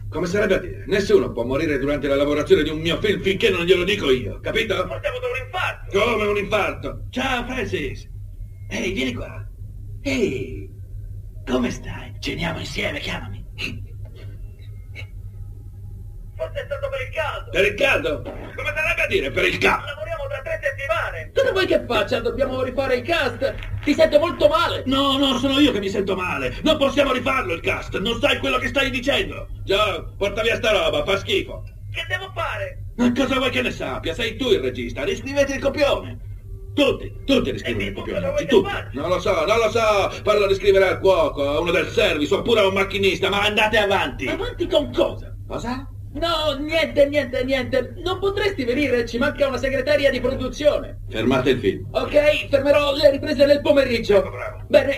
in cui doppia John Turturro.